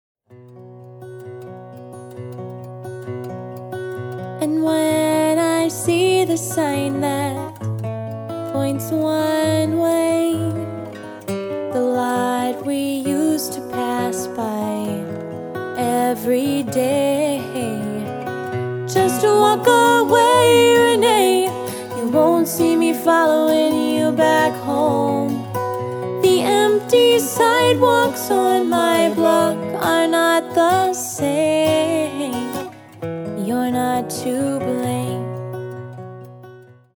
About 13 years ago I recorded and mixed a folk project.
I added some mastering effects to one of the songs.